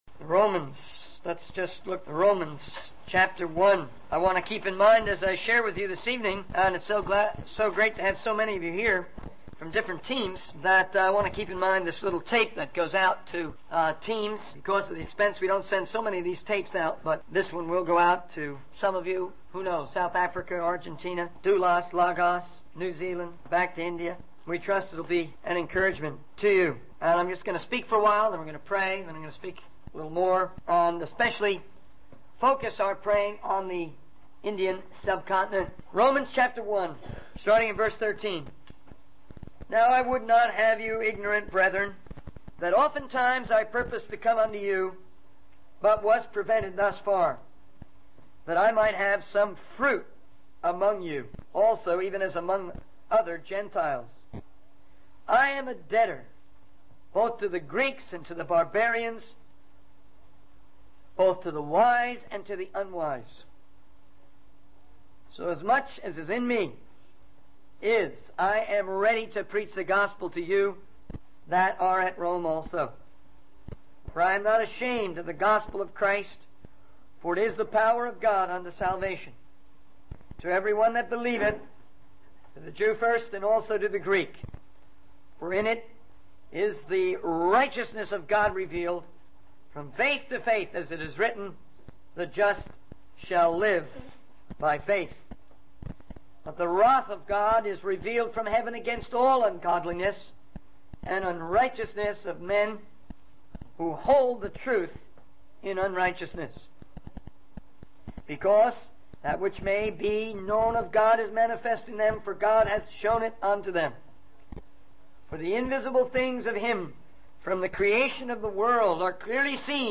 In this video, the speaker shares about their recent travels to Oman, Dubai, and India to spread the word of God. They mention the challenges faced in reaching out to Muslims, who they believe are the largest unreached people group in the world.